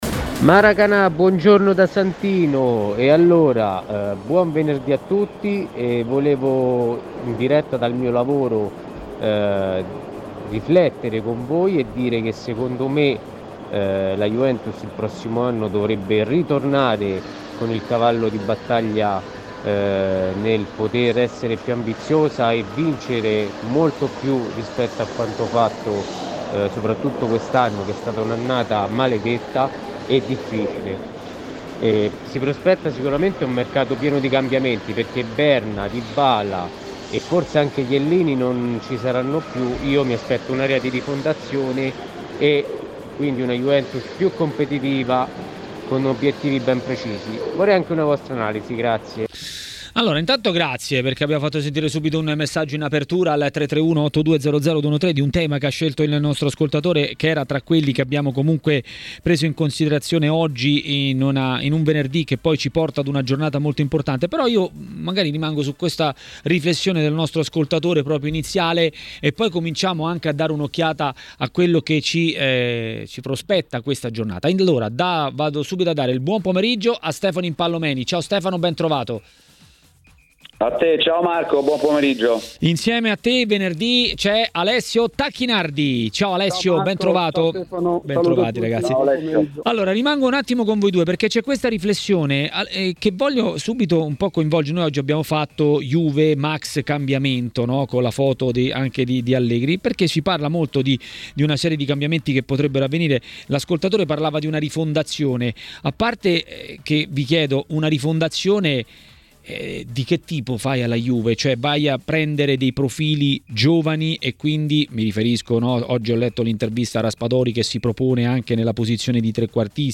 Il tecnico ed ex calciatore Alessio Tacchinardi a TMW Radio, durante Maracanà, ha commentato le news del giorno.